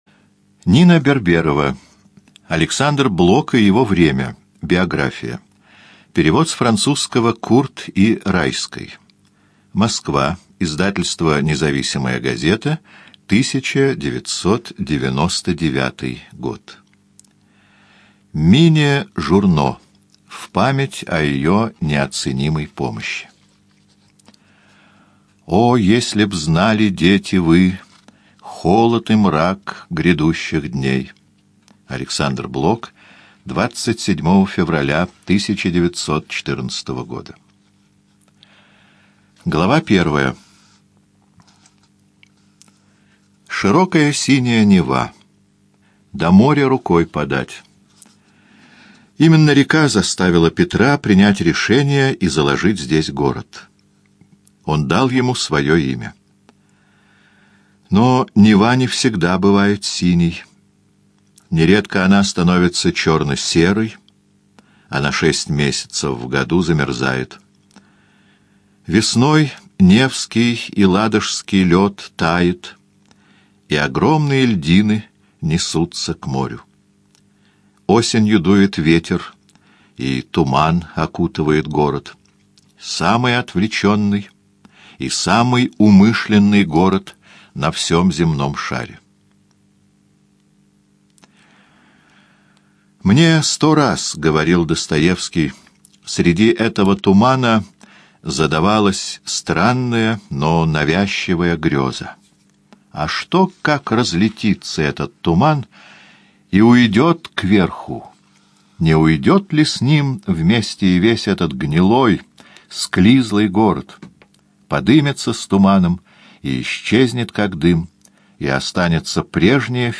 ЖанрБиографии и мемуары
Студия звукозаписиЛогосвос